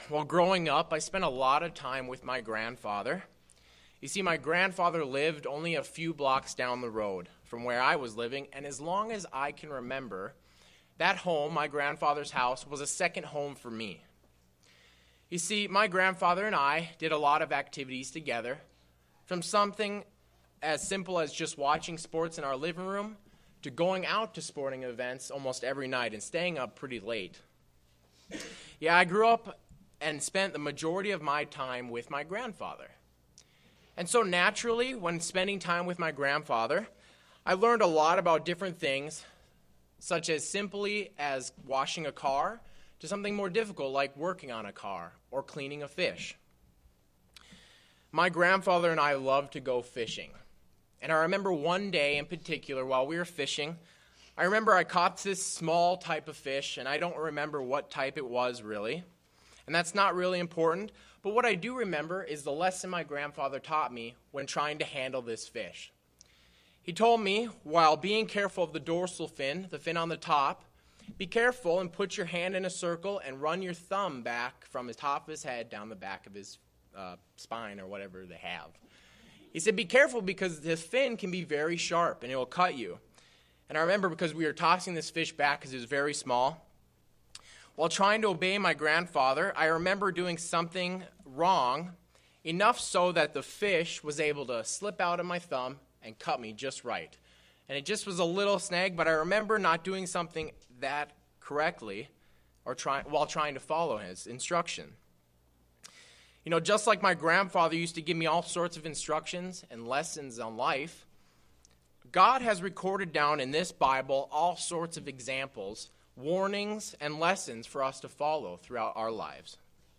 During Jesus's ministry He exposed the Pharisees as a wicked generation. This split sermon takes a look into the different types of the leavens of the Pharisees mentioned by Jesus and ways we as Christians can resist them.